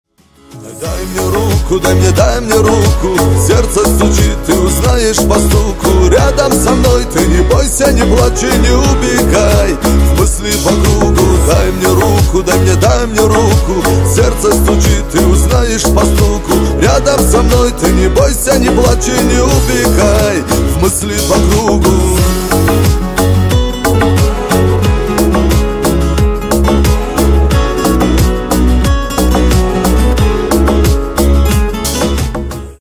Кавказские Рингтоны
Шансон Рингтоны